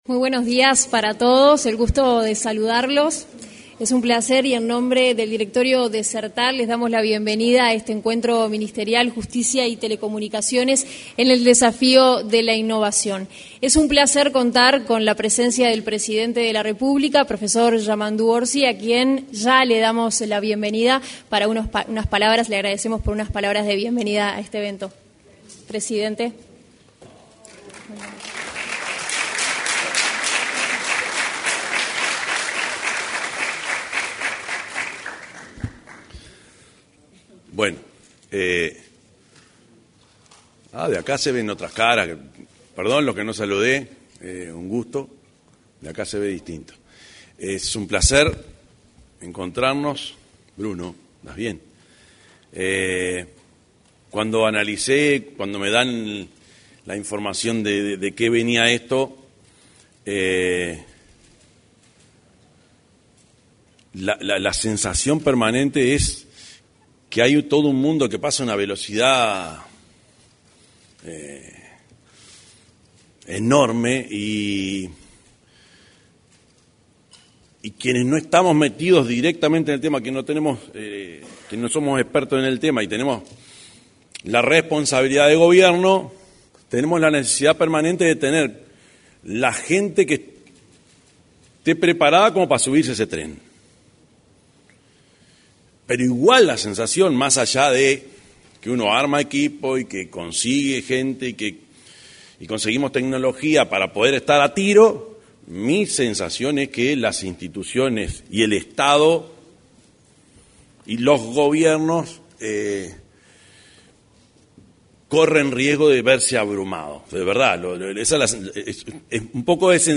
Palabras del presidente de la República, Yamandú Orsi, y la ministra de Industria, Fernanda Cardona, en la apertura del encuentro Justicia y